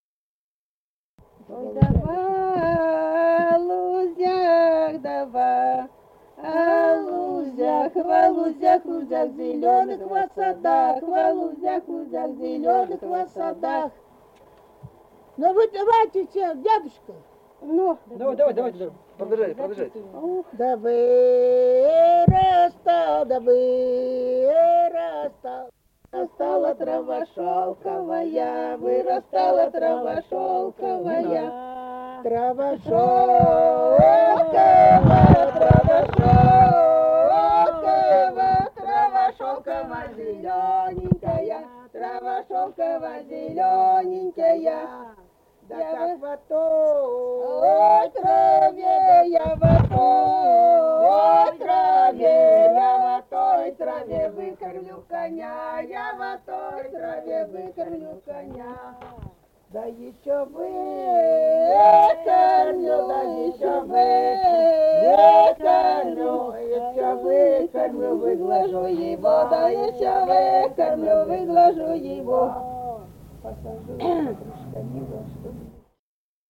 Сидит Дрема Ох, да во лузях (хороводная «лужошная», круговая) Республика Казахстан, Восточно-Казахстанская обл., Катон-Карагайский р-н, с. Коробиха.